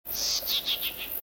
forest_bird1.mp3